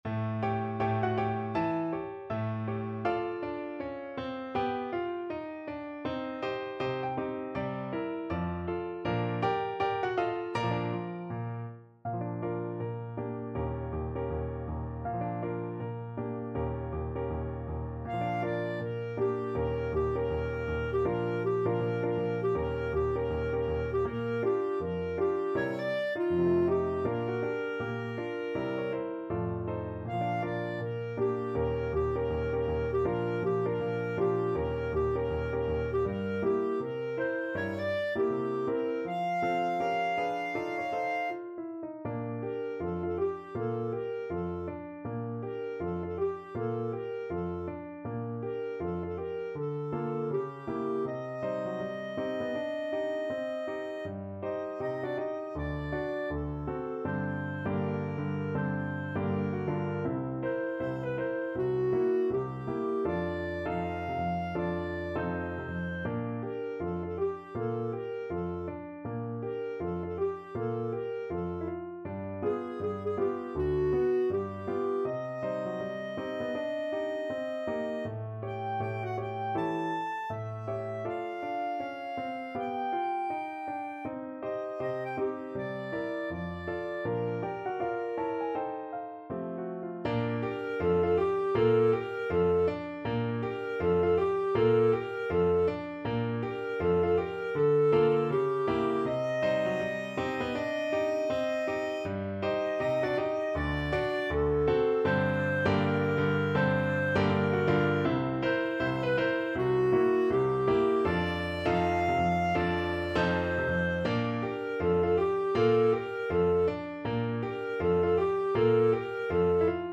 2/2 (View more 2/2 Music)
~ = 160 Moderato
Jazz (View more Jazz Clarinet Music)
Rock and pop (View more Rock and pop Clarinet Music)